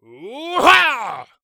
ZS重击1.wav
人声采集素材/男3战士型/ZS重击1.wav